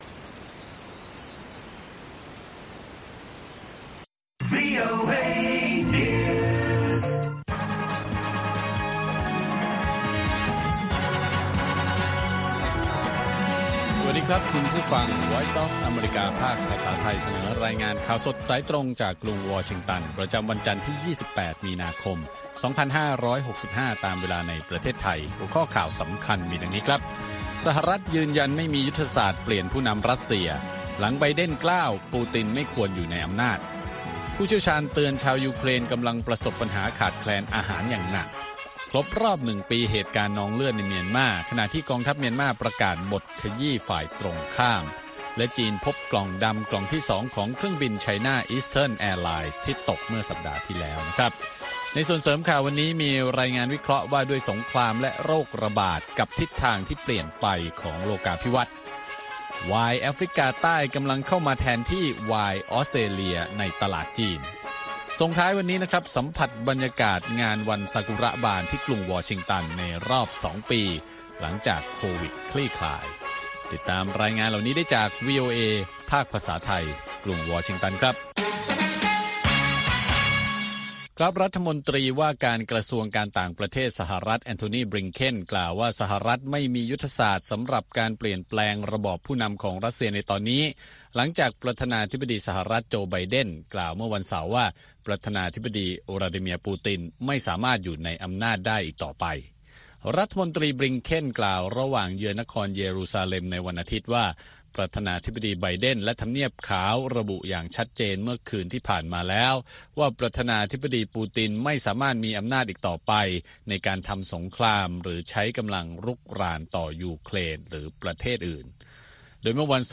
ข่าวสดสายตรงจากวีโอเอ ภาคภาษาไทย วันจันทร์ ที่ 28 มีนาคม 2565